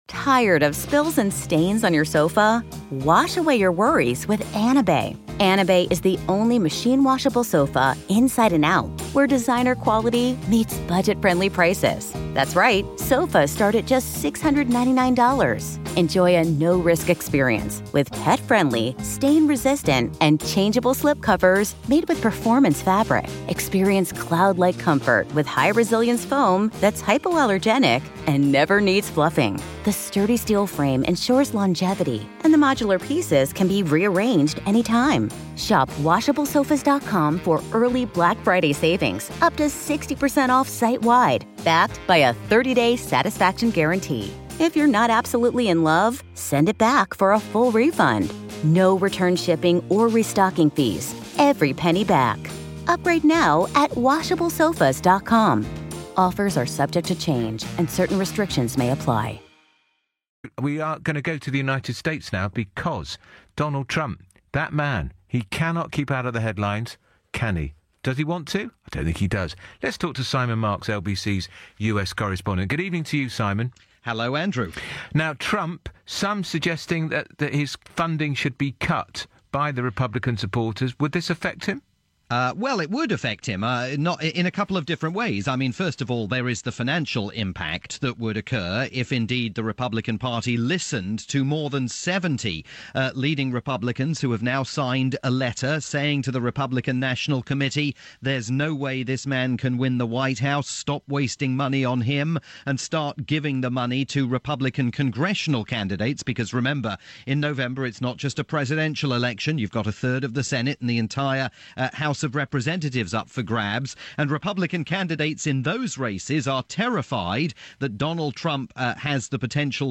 aired on LBC in the UK